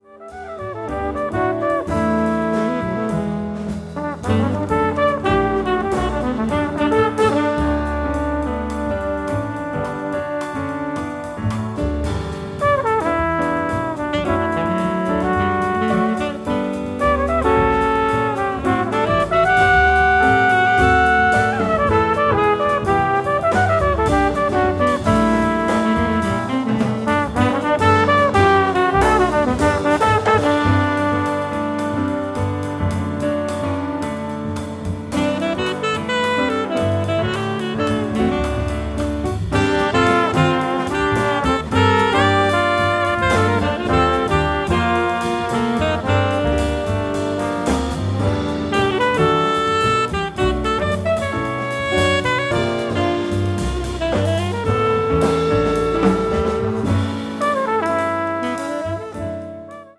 Triple threat pianist
trumpeter
jazz